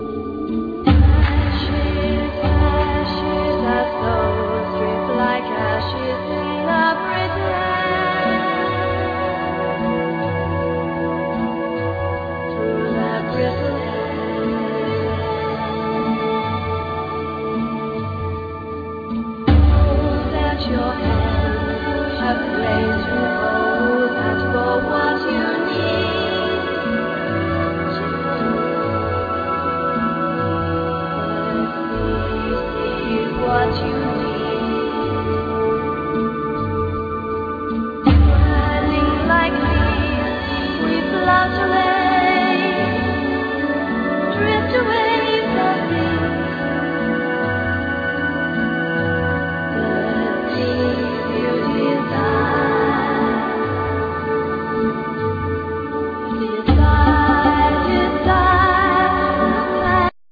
Disc 1(Vocal Tracks)
Vocals,Viola
Flute
Cello
Violin
Electronics